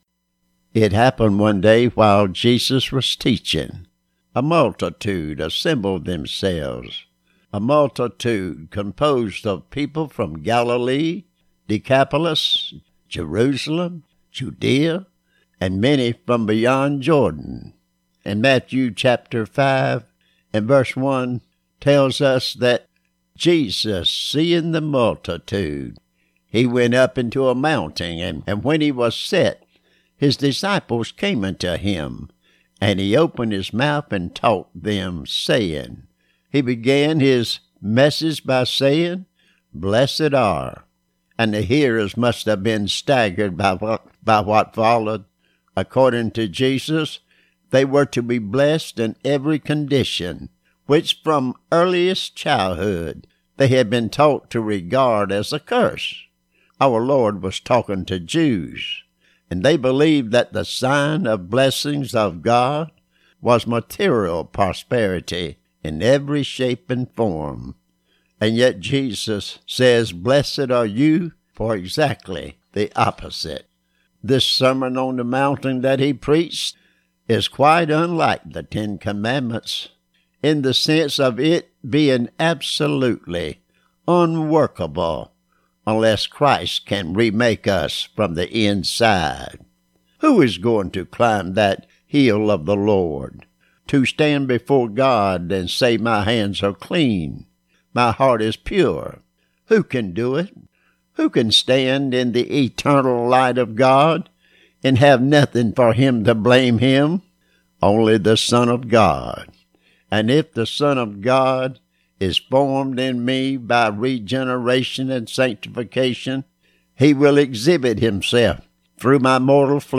Sermon on The Mountain _Tuesday